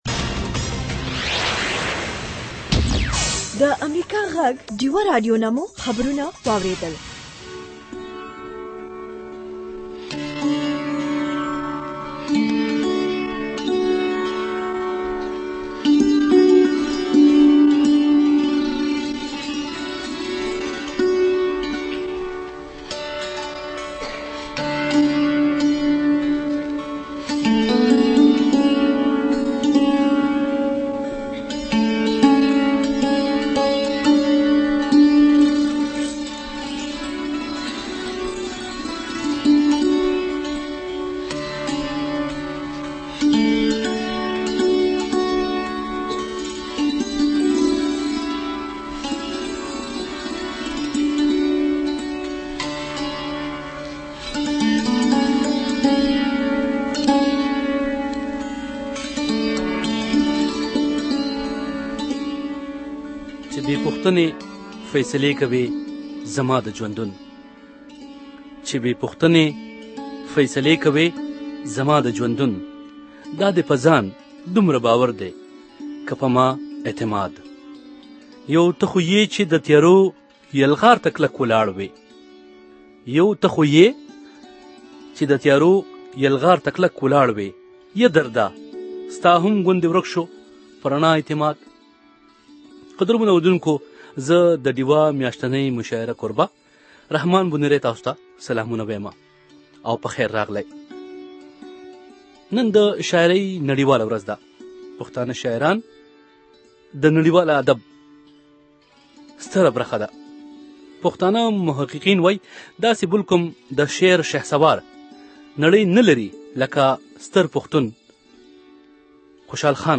د ډيوه مشاعره واورئ-
World Poetry-Day